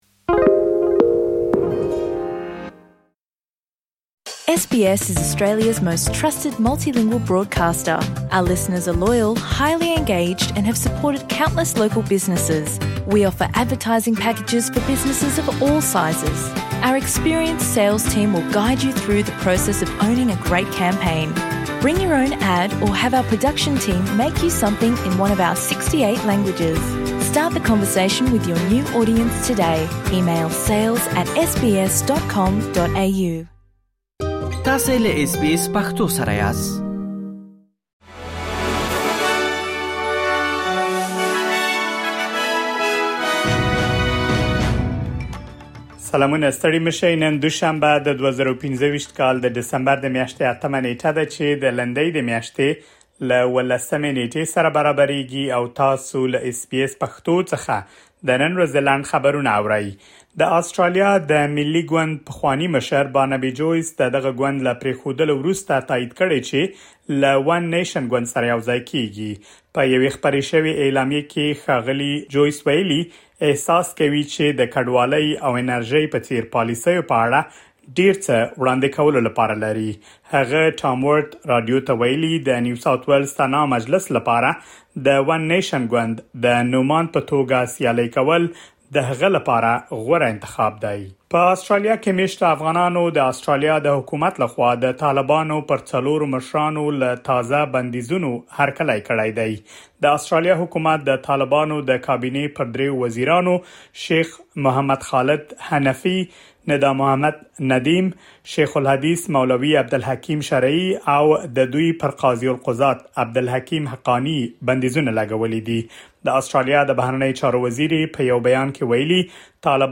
د اس بي اس پښتو د نن ورځې لنډ خبرونه دلته واورئ.